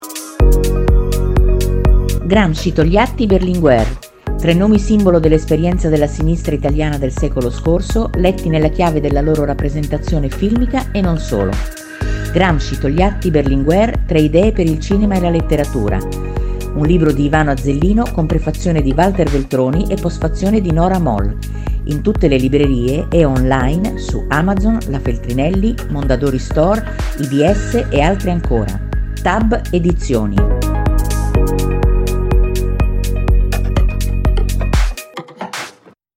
Spot di Radio Power Italia, ottobre 2022